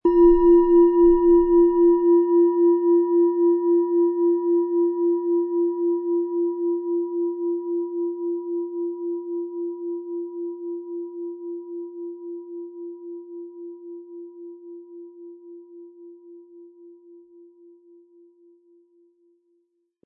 Von Hand getriebene Klangschale mit dem Planetenklang Platonisches Jahr aus einer kleinen traditionellen Manufaktur.
Ein unpersönlicher Ton.
• Tiefster Ton: Mond
MaterialBronze